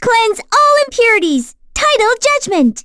Lilia-Vox_Skill6.wav